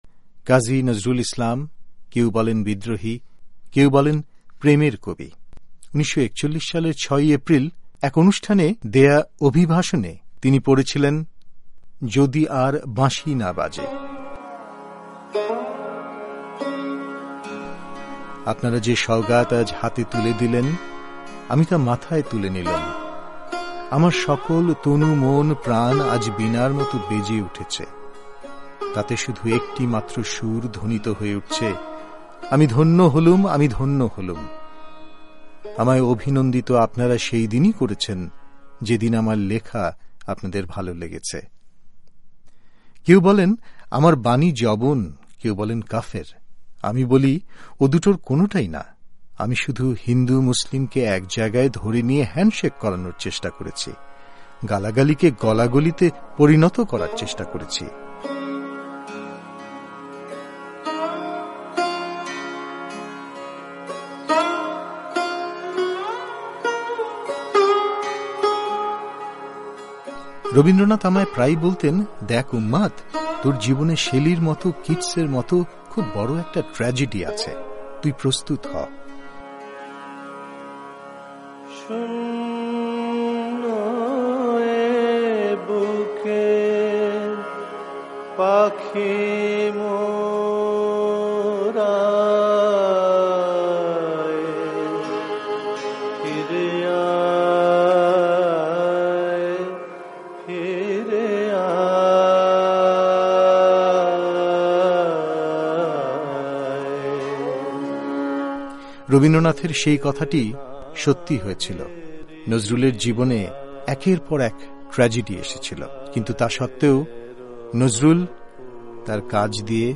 একটি বিশেষ সাক্ষাৎকার